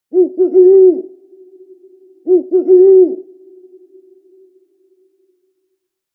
Scary Owl Sound Effect
Eerie, echoing owl hoot in the dead of night, reverberating through a misty forest. Hoot owl sounds.
Scary-owl-sound-effect.mp3